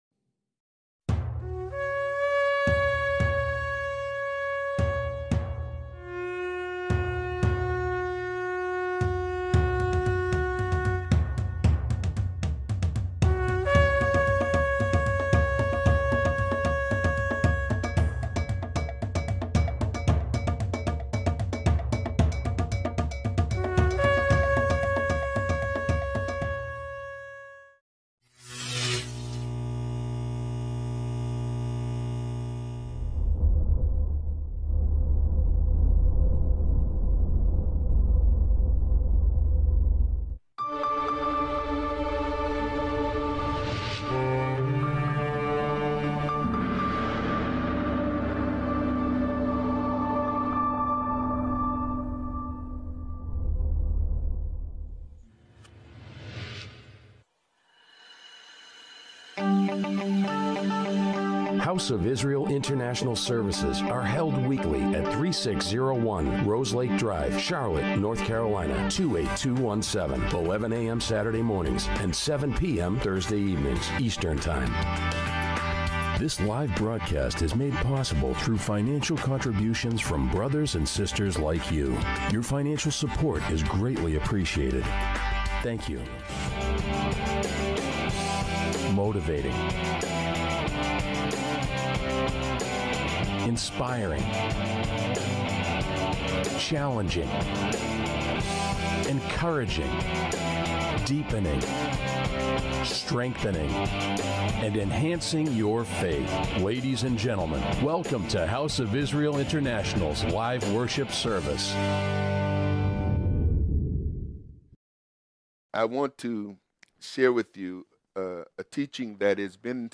This is Part 1 of the two-part teaching series Understanding Grace. When Grace is used in the Biblical context, it is truly a beautiful gift from YeHoVaH.